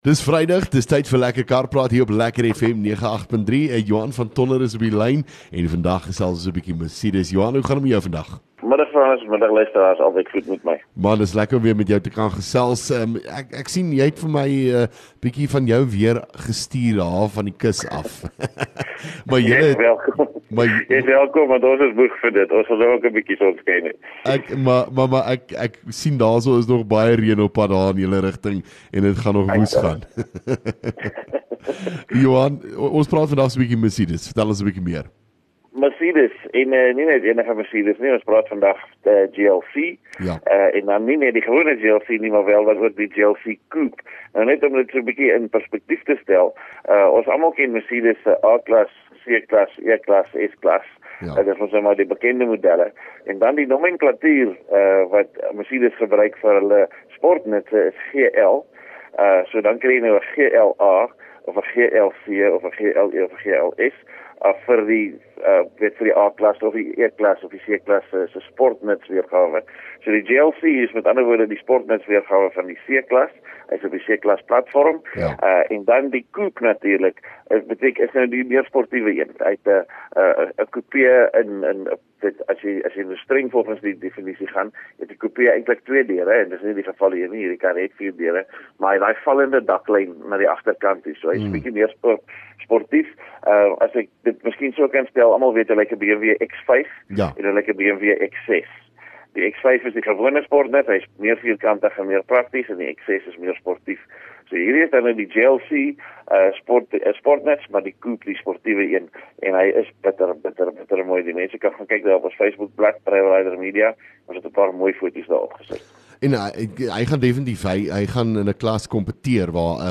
LEKKER FM | Onderhoude 12 Apr Lekker Kar Praat